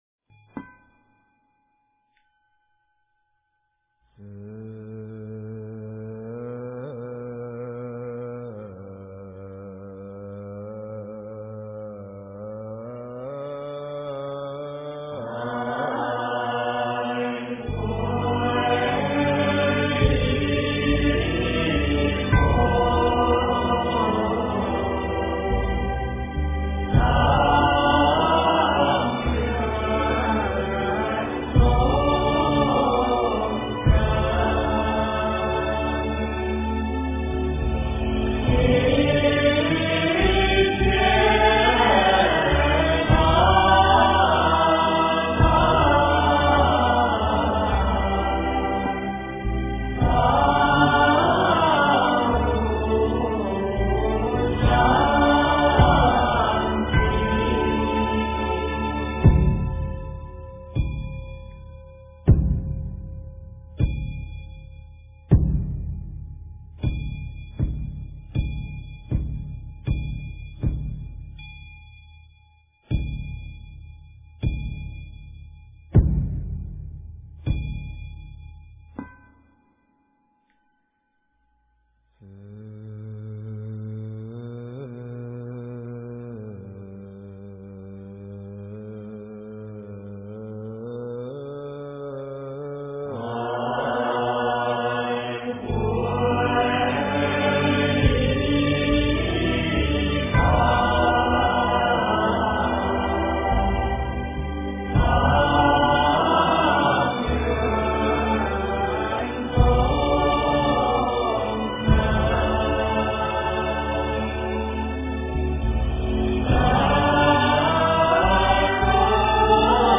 八十八佛大忏悔文-三皈依--僧团 经忏 八十八佛大忏悔文-三皈依--僧团 点我： 标签: 佛音 经忏 佛教音乐 返回列表 上一篇： 往生咒--僧团 下一篇： 晚课-赞佛偈--僧团 相关文章 南无毗卢遮那佛-佛号拜愿--慈悲三昧水忏 南无毗卢遮那佛-佛号拜愿--慈悲三昧水忏...